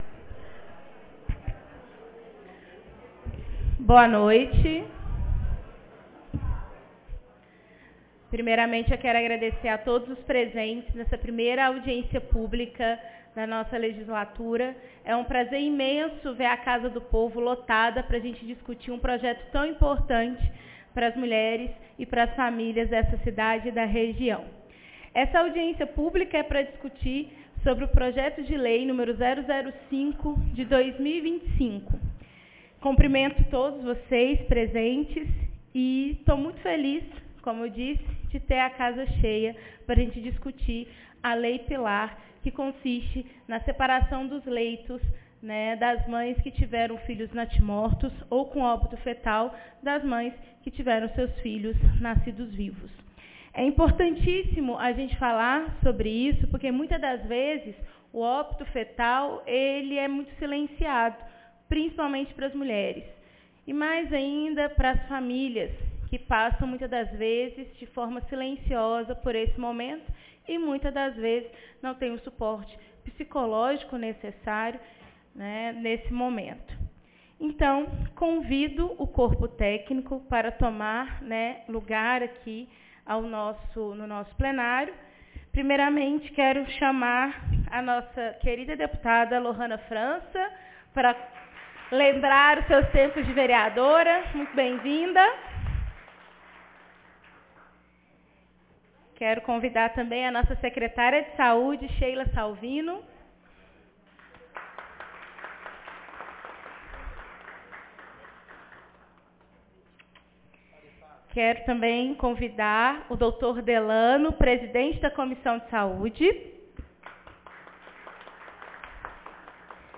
Audiência pública 2025